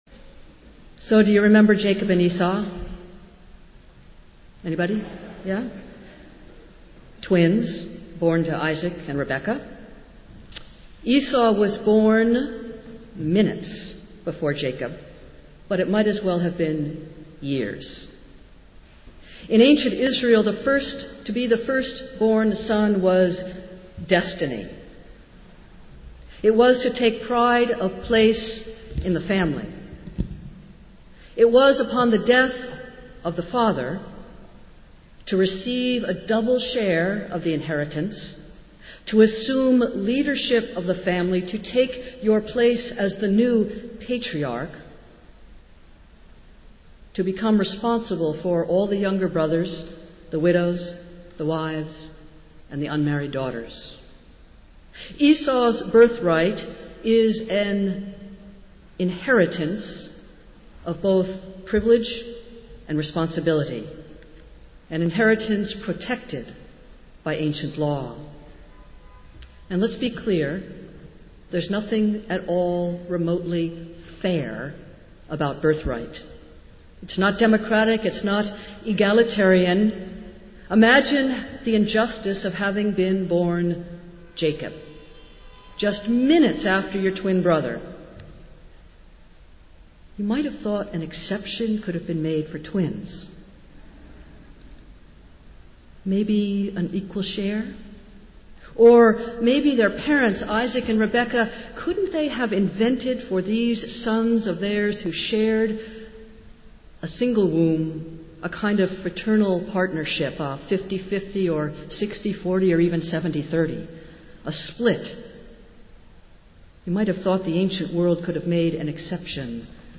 Festival Worship - Third Sunday after Pentecost